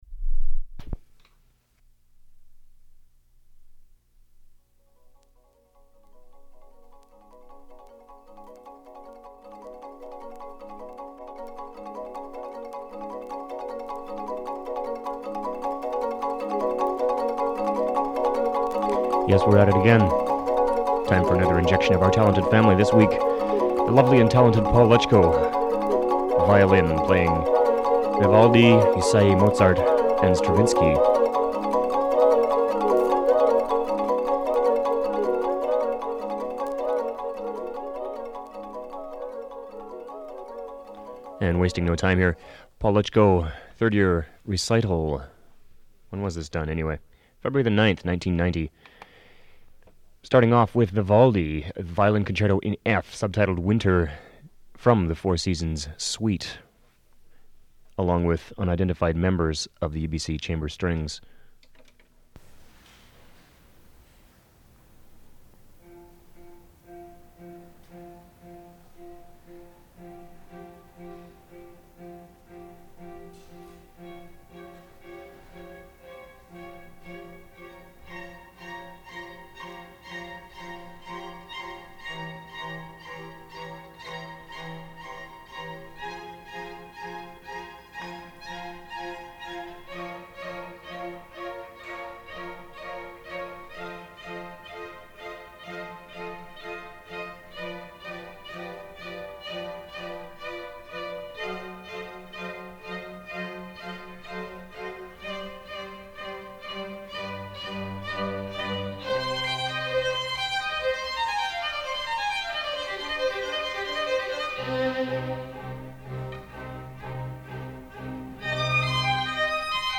violin
3rd yr recital
piano